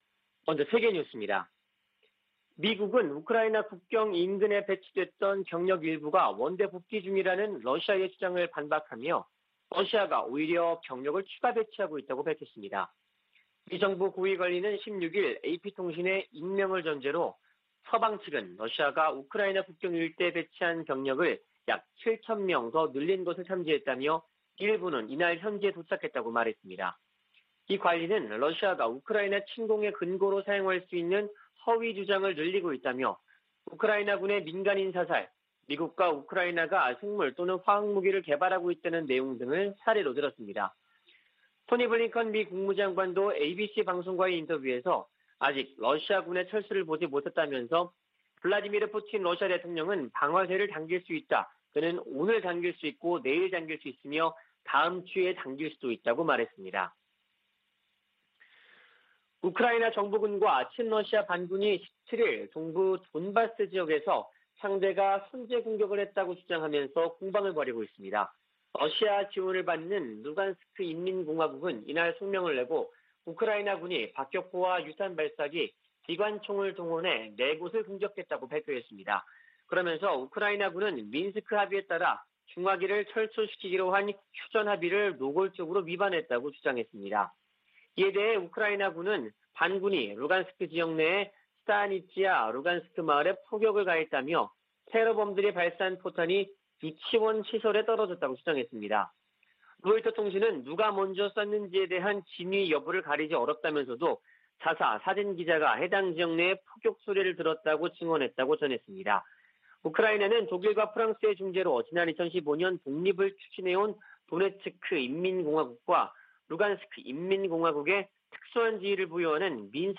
VOA 한국어 '출발 뉴스 쇼', 2021년 2월 18일 방송입니다. 미국이 핵탄두 탑재 가능한 B-52H 전략폭격기 4대를 괌에 배치했습니다. 미국의 전문가들은 필요하다면 한국이 우크라이나 사태 관련 미국 주도 국제 대응에 동참해야한다는 견해를 제시하고 있습니다. 북한이 한 달 새 가장 많은 미사일 도발을 벌였지만 미국인들의 관심은 낮은 것으로 나타났습니다.